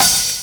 HH 20.wav